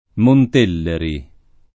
[ mont % lleri ]